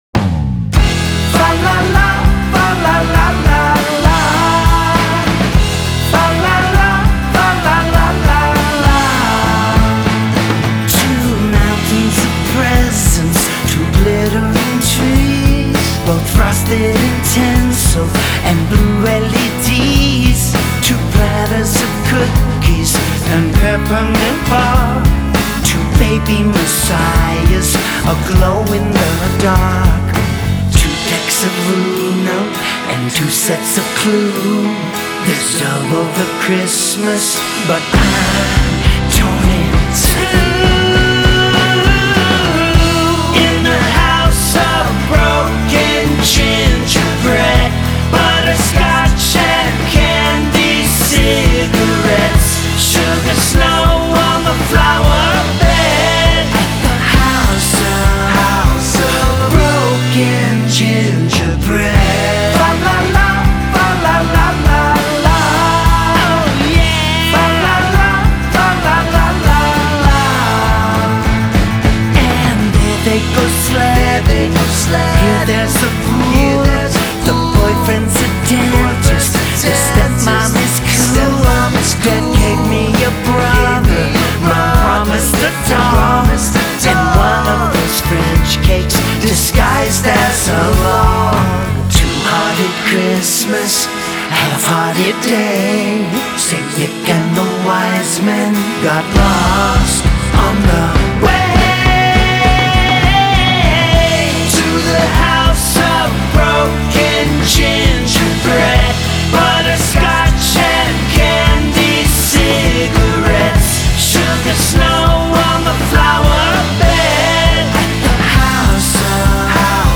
festive music
a superior poprock tune